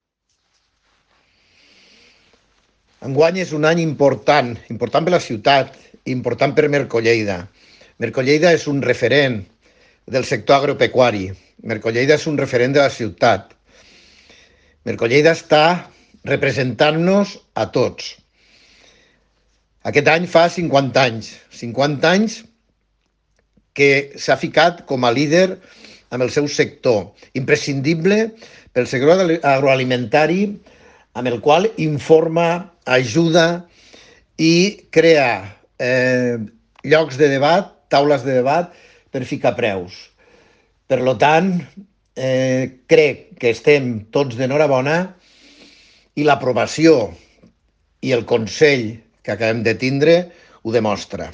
tall-de-veu-del-tinent-dalcalde-paco-cerda